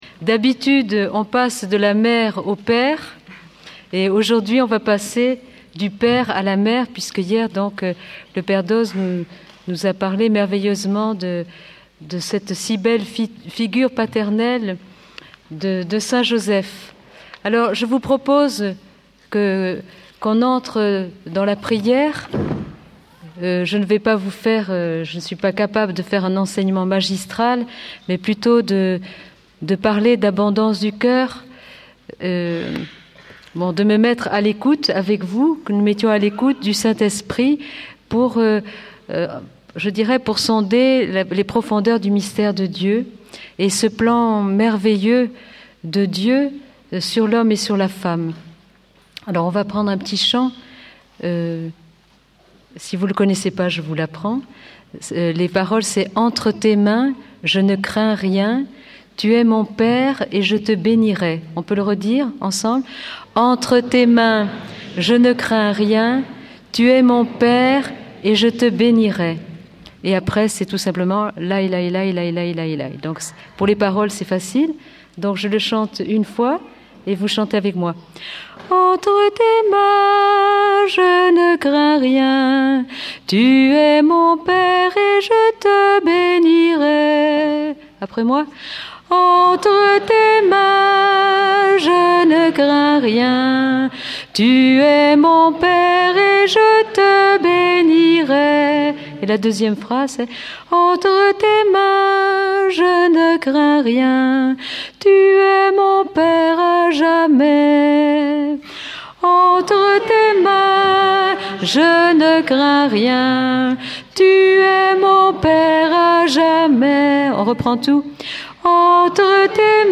Nous avons un d�fi � relever : la r�conciliation de l'homme et la femme. Un enseignement �clair� par la Parole de Dieu et de l'exp�rience d'une femme qui en a rencontr� tant d'autres ! Enregistrement r�alis� au cours de la session de la Communaut� des B�atitudes "Lourdes 2004" Intervenant(s